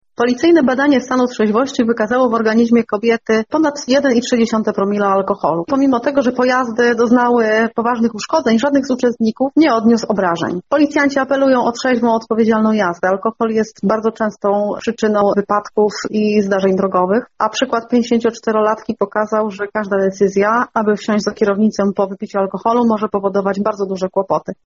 Wypadek-Kluczkowice.mp3